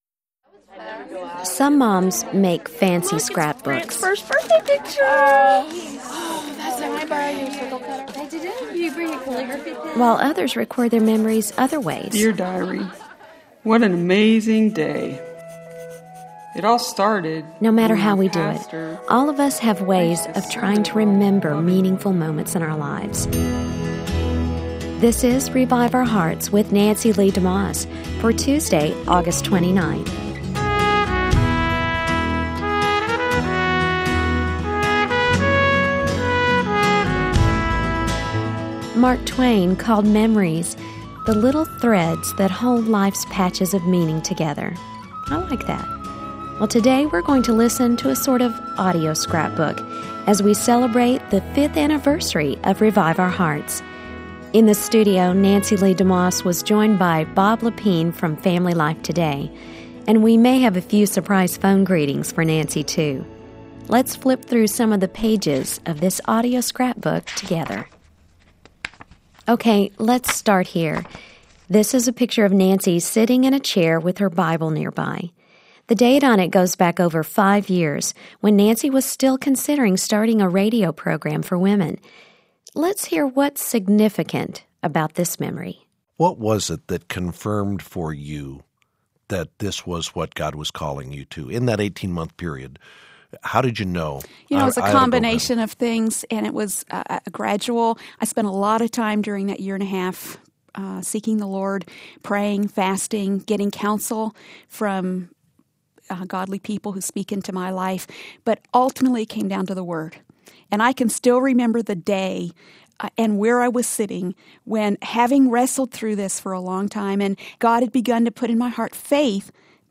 Scrapbooks are a great way to preserve memories and celebrate life. Today on Revive Our Hearts you’ll hear an audio scrapbook celebration.
| Topics: News & Updates Women chattering in the background about scrapbooking.